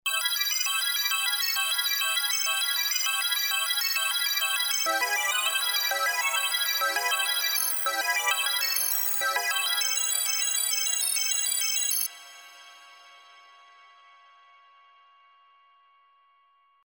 Sound per Zufallsgenerator
Diese drei Sounds hat Icarus ohne weiteres Zutun gewürfelt: